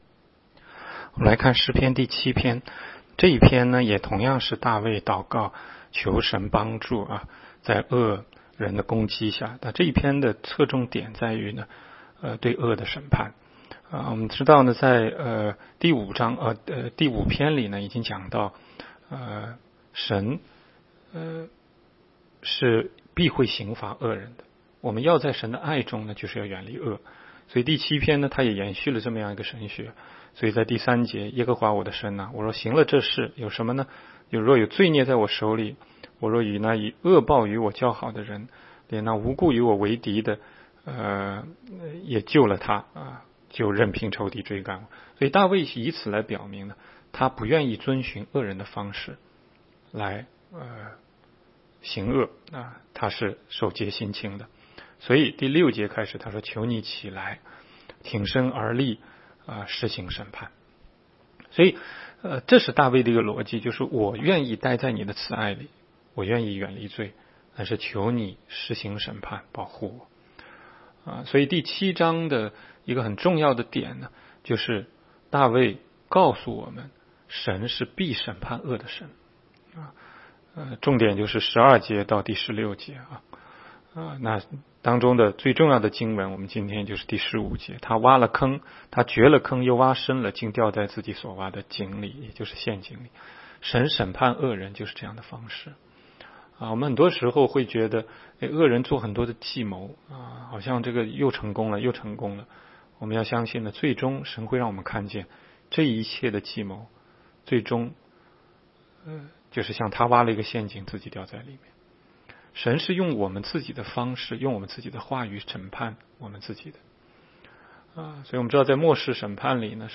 16街讲道录音 - 每日读经-《诗篇》7章